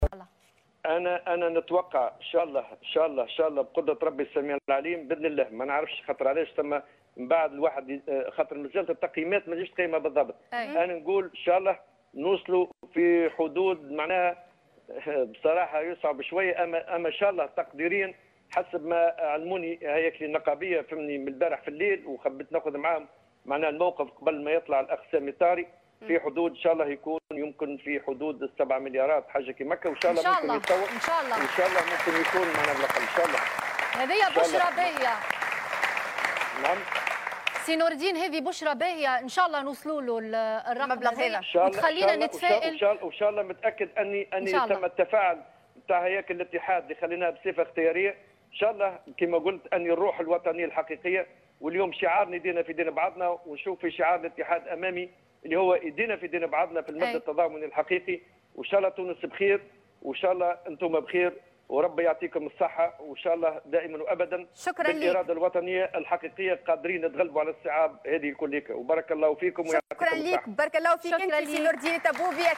وقال في مداخلة هاتفية مع حصة "تيليتون" على قناة "الوطنية" لجمع التبرعات لفائدة المتضررين من فيضانات نابل إن الهياكل النقابية أبلغته منذ يوم أمس انها تتوقع جمع تبرعات بقيمة 7 ملايين دينار رغم أن هذا الرقم صعب، بحسب تعبيره.